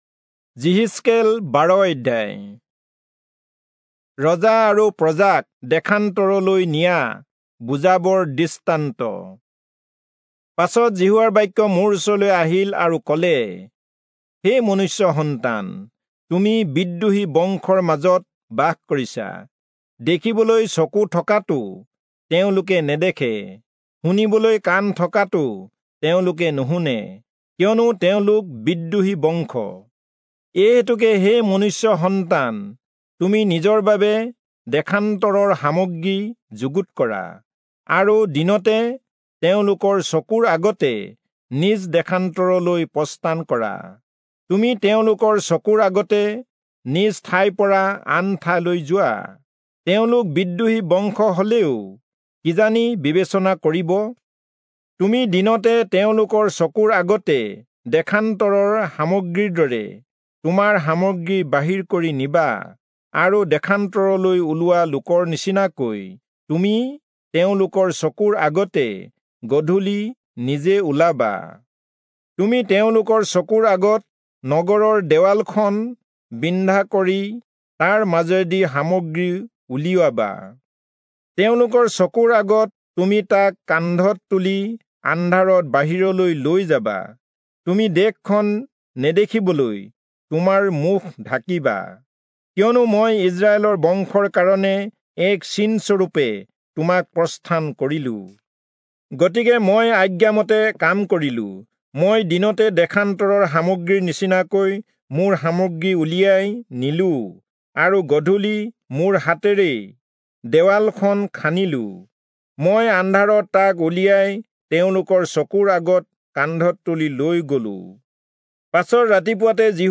Assamese Audio Bible - Ezekiel 7 in Bhs bible version